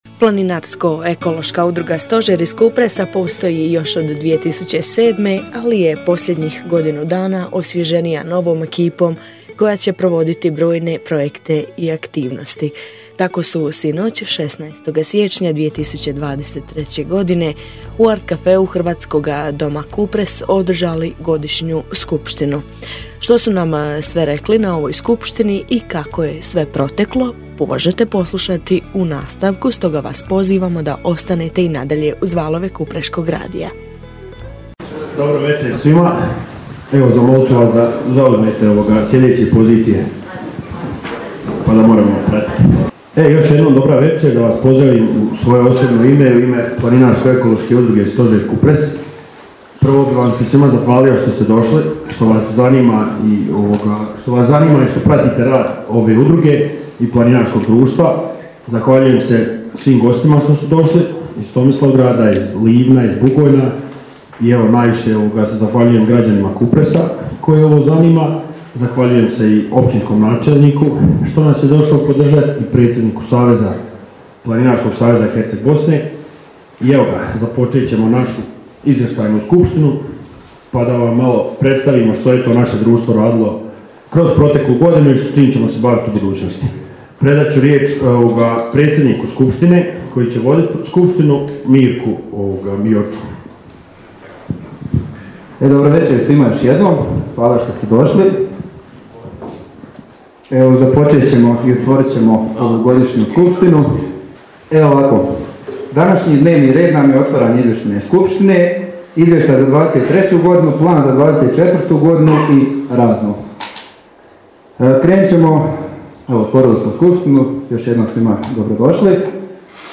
Godišnja Skupština Planinarsko ekološke udruge "Stožer" Kupres održana je jučer, 16. siječnja 2024. godine u prostoru Hrvatskoga doma Kupres.
Godisnja_skupština_planinarsko_e.mp3